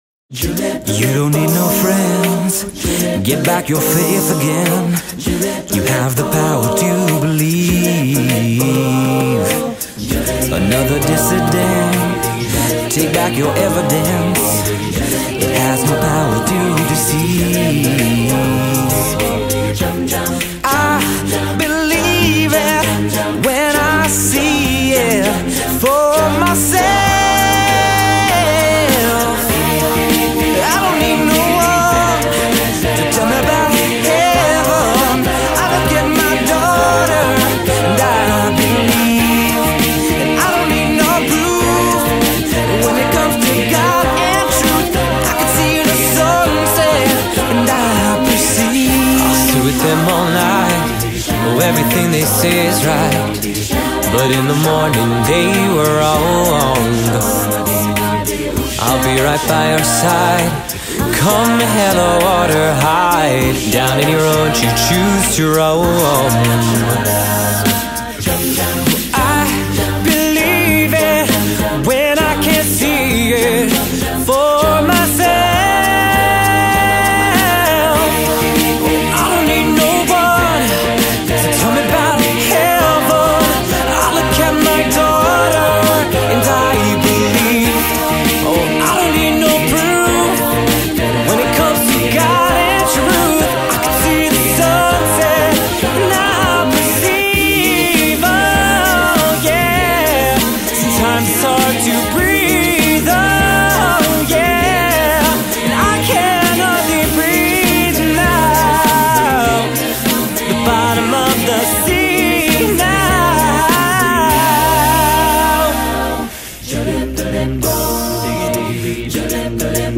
Genre: Secular
Contains solos: Yes